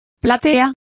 Complete with pronunciation of the translation of orchestras.